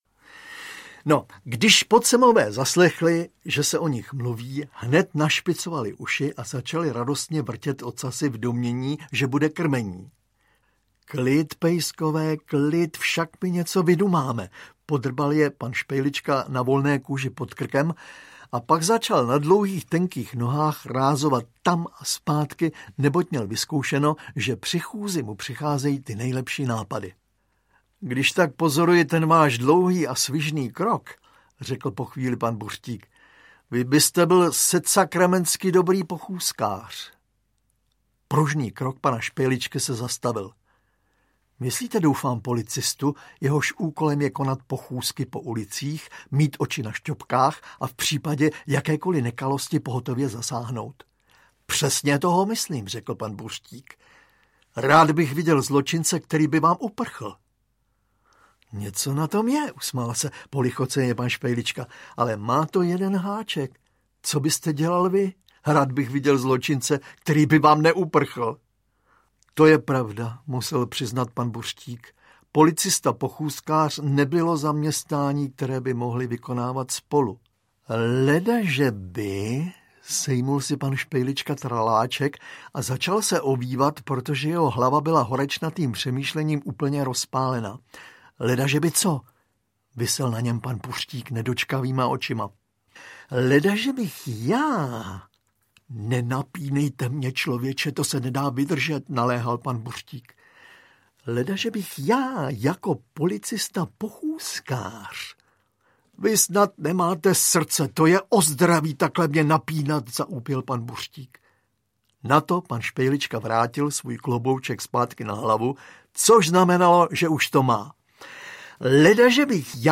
Ukázka z knihy
Autor je v tomto případě jedinečným a nejlepšíminterpretem, přítel Jaroslav Uhlíř pak nejlepším hudebním skladatelem.
• InterpretZdeněk Svěrák